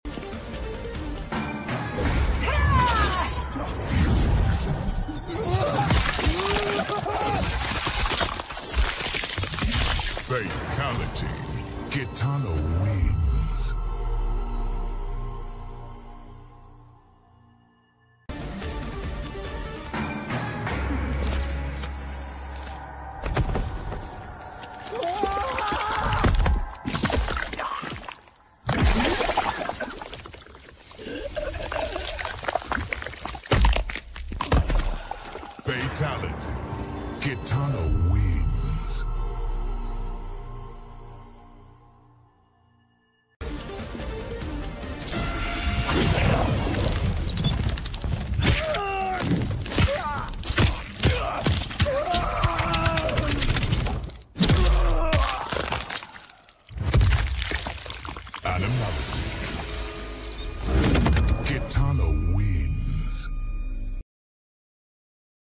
Fatality Kitana Mortal Kombat MK Sound Effects Free Download